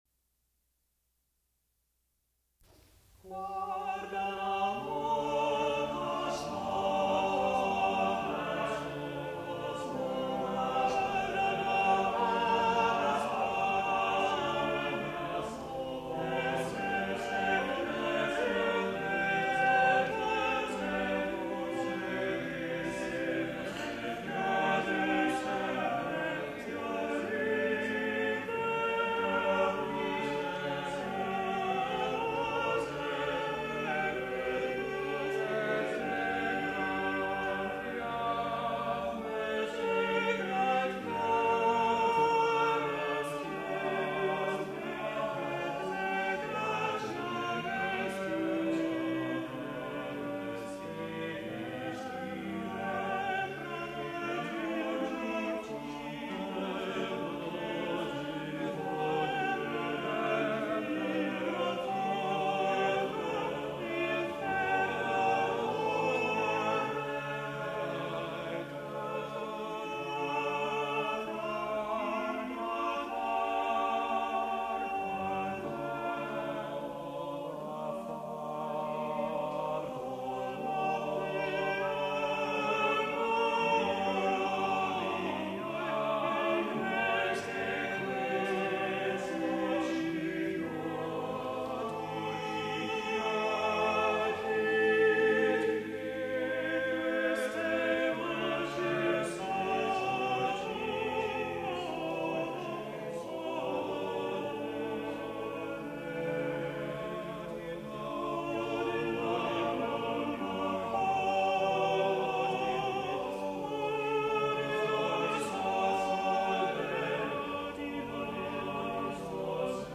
This six-part madrigal is the entre act between the first and second acts of the play, ‘Il Commodo’, presented at Duke Cosimo I’s wedding festivities.
This performance is unaccompanied.
| Vocal Ensemble, 'Seven Ages' 1979